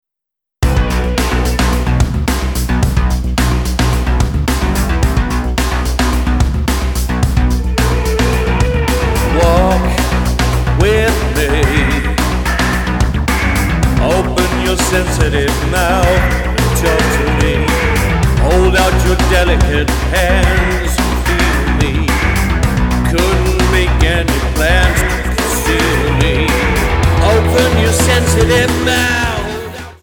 Tonart:Bm Multifile (kein Sofortdownload.